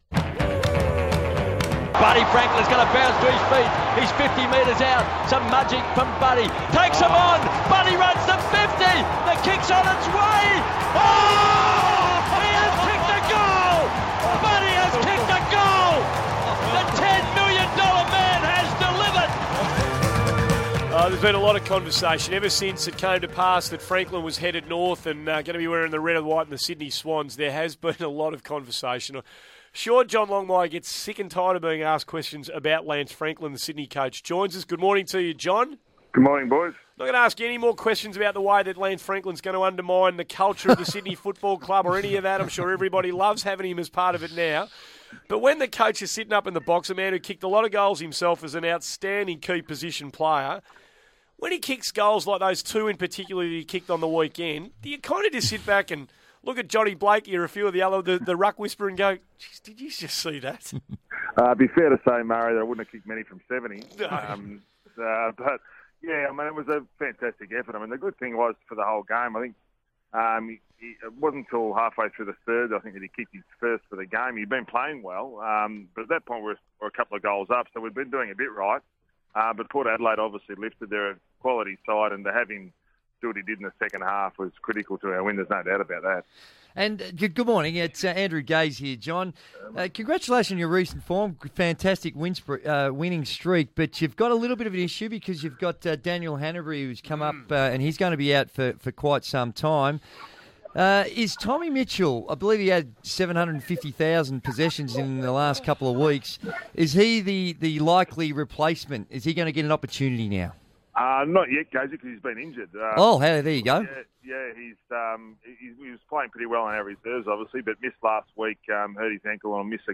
Sydney Swans coach John Longmire appeared on 1116SEN's Morning Glory program on Thursday June 19, 2014